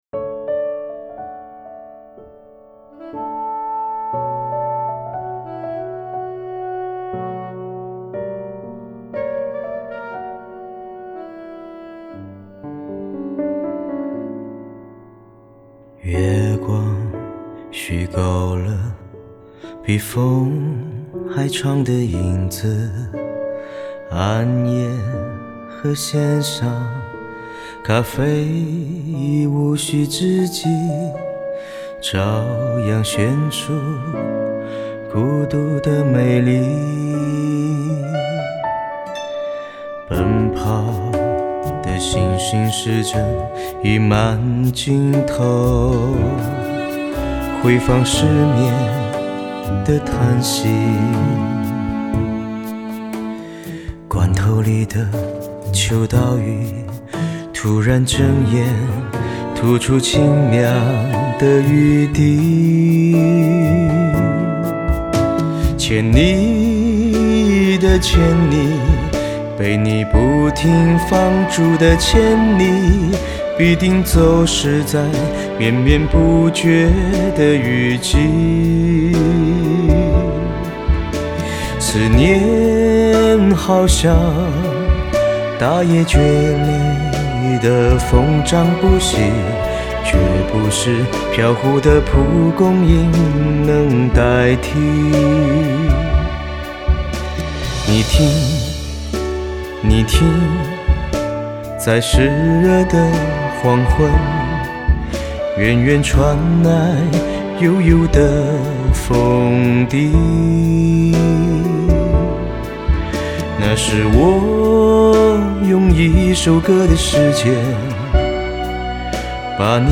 男声演唱